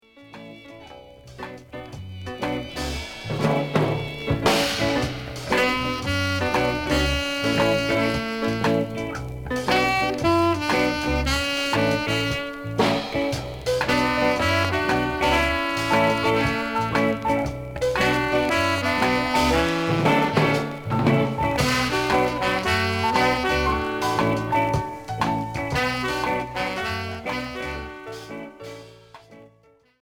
Rockabilly et Reggae Unique 45t